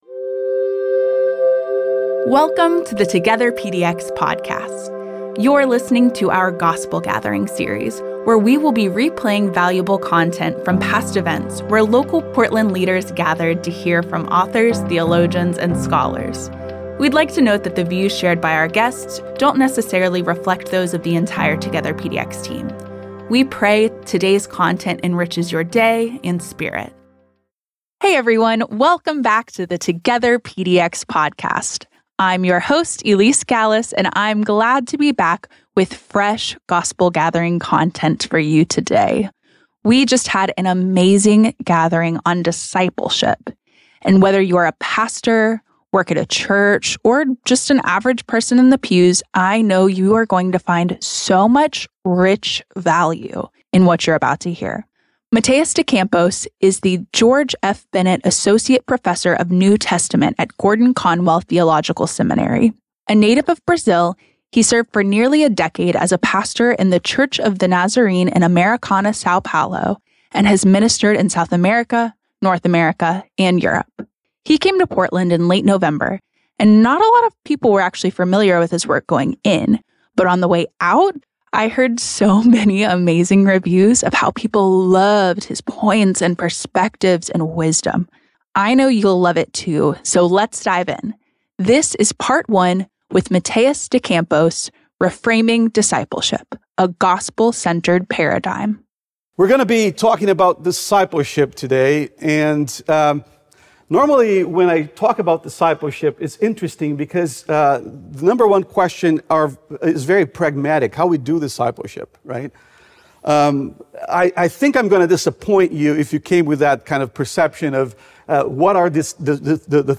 In this November 2025 lecture